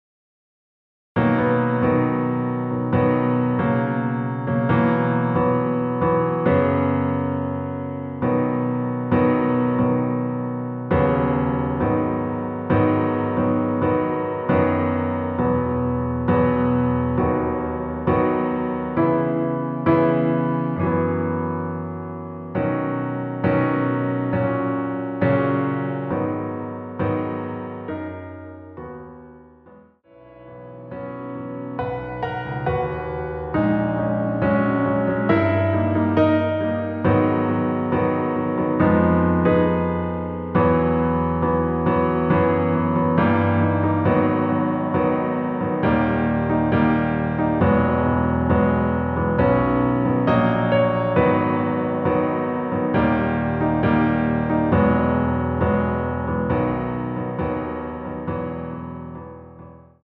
반주를 피아노 하나로 편곡하여 제작하였습니다.
원키에서(-2)내린(Piano Ver.) (1절+후렴) MR입니다.